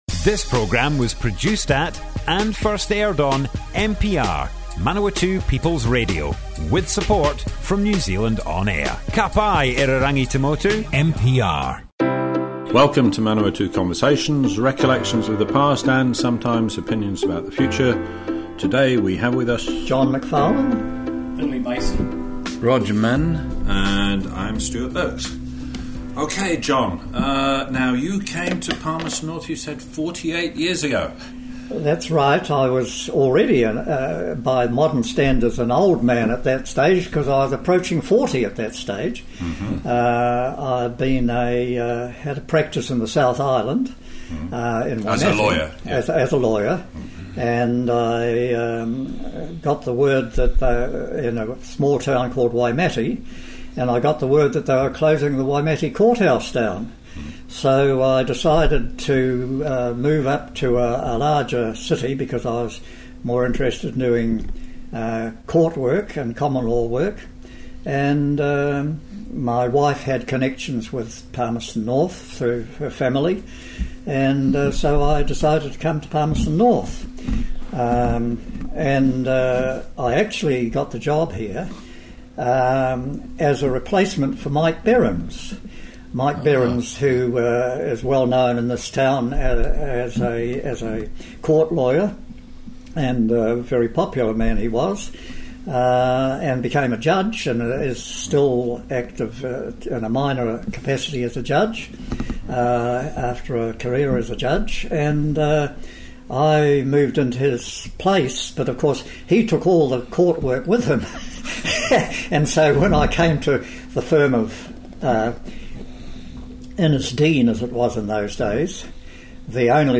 Manawatu Conversations More Info → Description Broadcast on Manawatu People's Radio 21 August 2018.
oral history